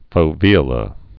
(fō-vēə-lə)